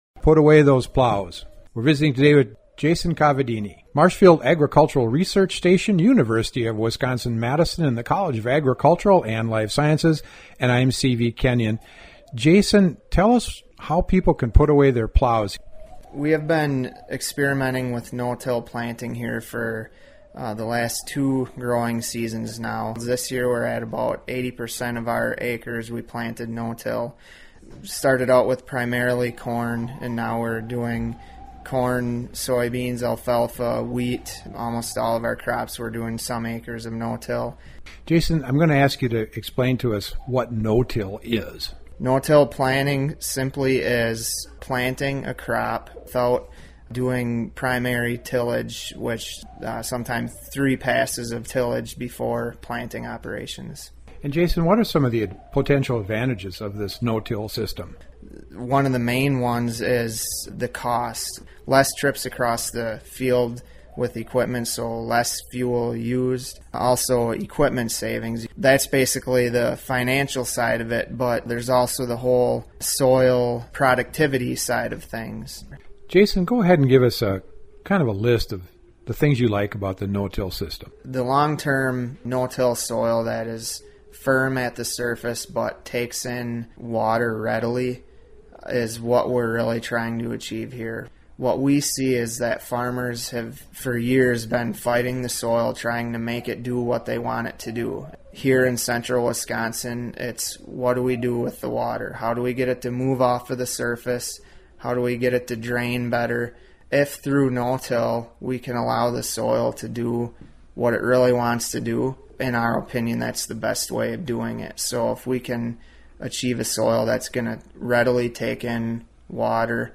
Agronomist